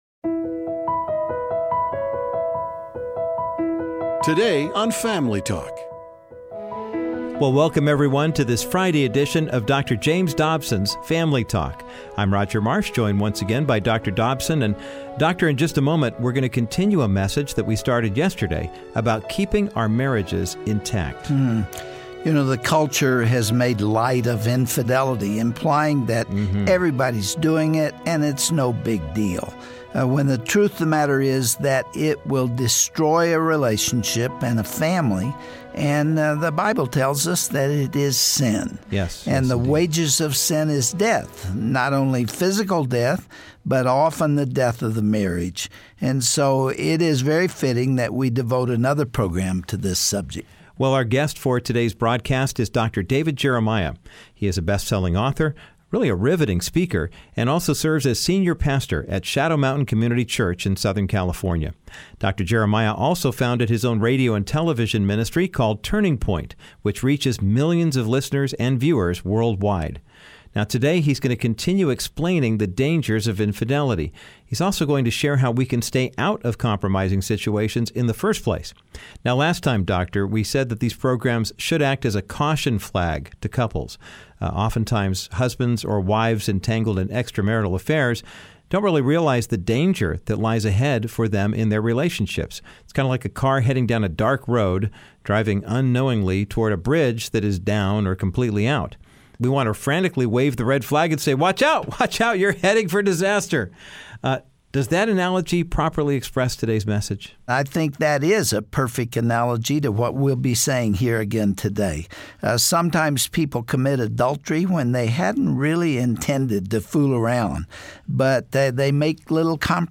Have you established defenses around your marriage? pastor and author Dr. David Jeremiah concludes his sermon on the threats and deceptiveness of adultery. He warns couples to take this issue seriously, and set up realistic boundaries around their marriage.